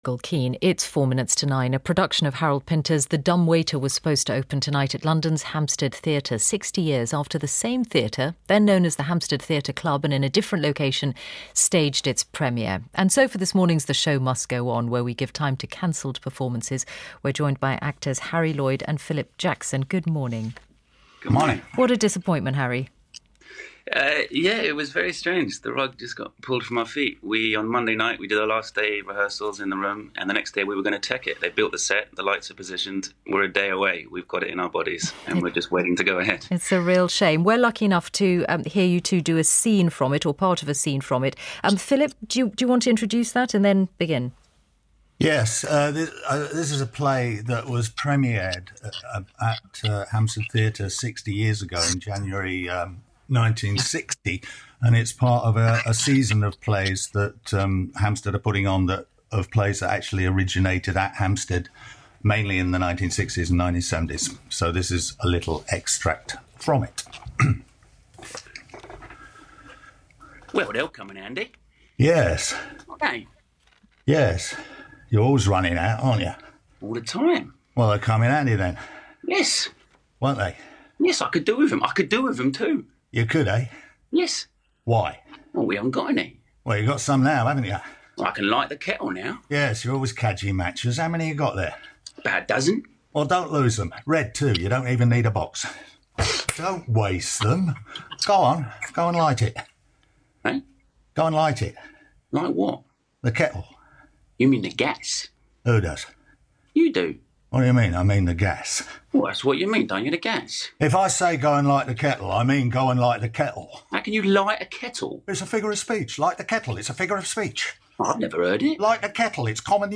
On Wednesday 25 March, which was due to be the opening night for the 60th anniversary production of Harold Pinter'The Dumb Waiter, actors Philip Jackson and Harry Lloyd spoke to Mishal Husain on Radio 4's Today programme.
They also performed an extract as part of 'The Show Must Go On' - a segment dedicated to performances postponed due to COVID-19.